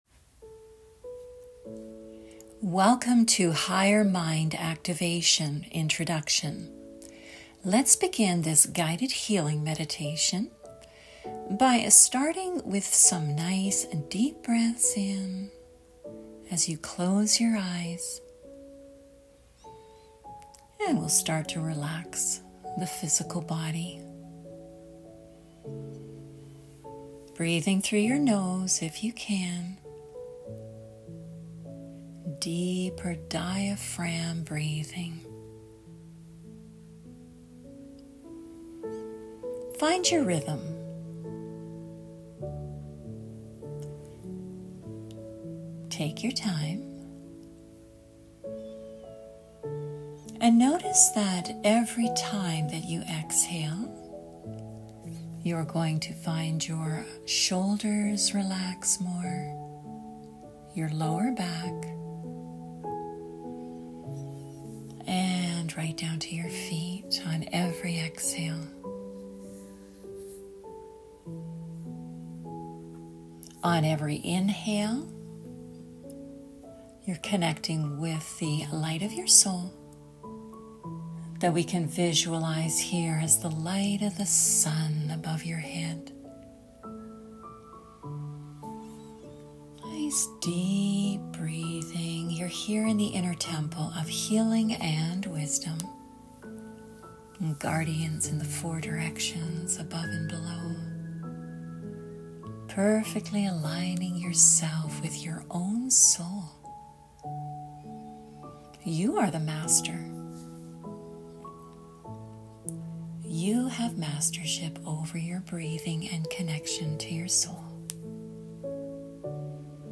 Free – Guided Healing Meditation – 17 minutes MindGenesis™ Introduction Activation